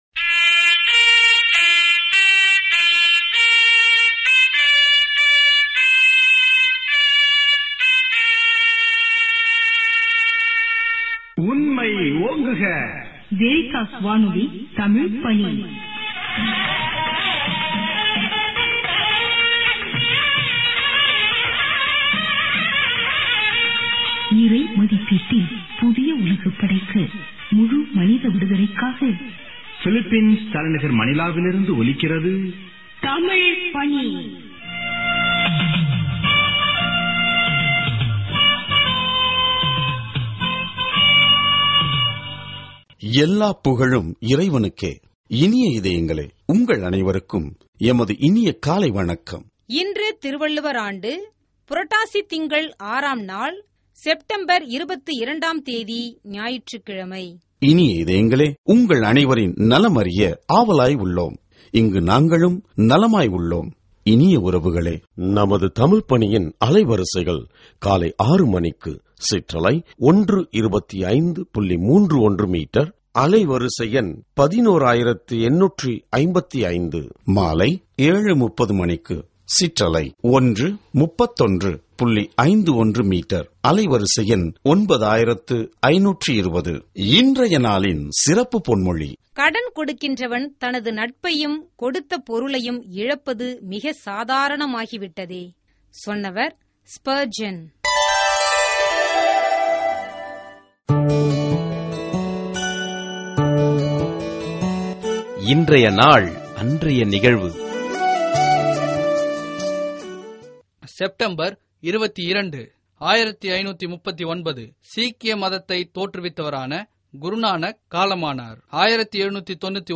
Directory Listing of mp3files/Tamil/Homilies/Ordinary Time/ (Tamil Archive)